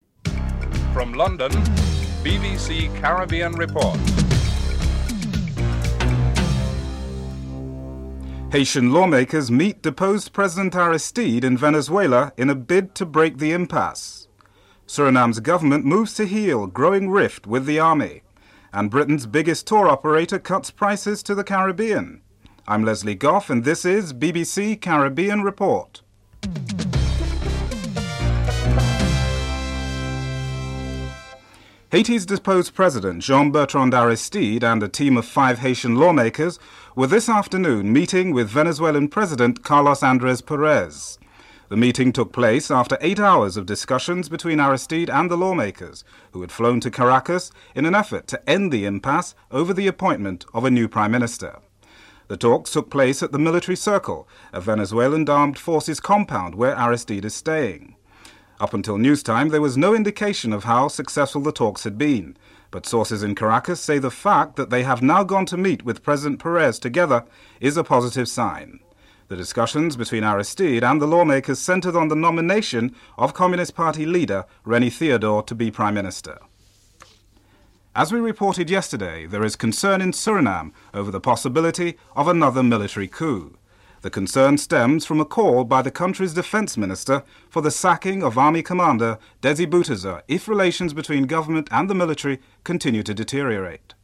This recording reports on a meeting held between ousted President Jean-Bertrand Aristide and the Venezuelan President; on the possibility of a military coup in Suriname; on the Conference of Commonwealth Speakers; price cuts by Britain's largest tour operator; on the election campaign in Guyana and on two of Britains sportsmen in the fields of boxing and cricket. Interviews are held with various individuals from some of the regions covered.
1. Headlines (00:00-00:31)